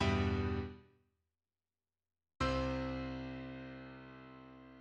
The chords that open and close Stravinsky's Symphony of Psalms
The two chords that open and close Igor Stravinsky's Symphony of Psalms have distinctive sonorities arising out of the voicing of the notes.